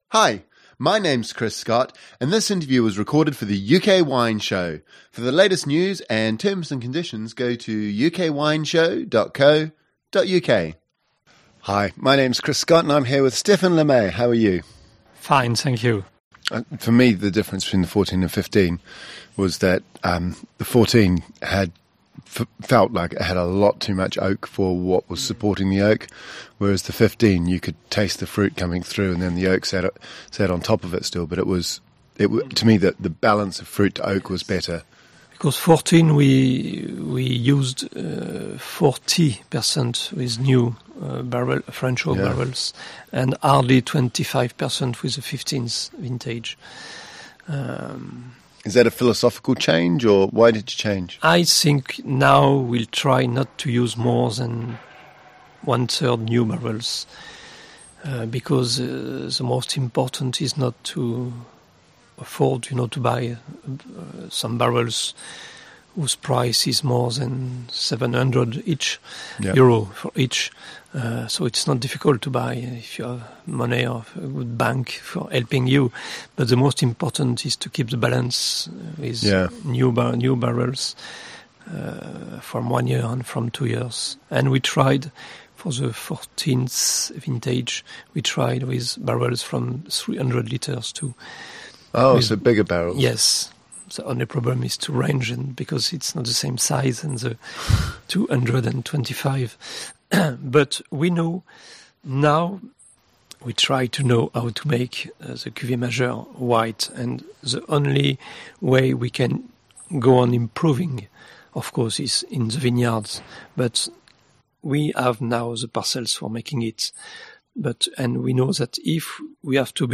The music used for the UK Wine Show is Griffes de Jingle 1 by Marcel de la Jartèle and Silence by Etoile Noire.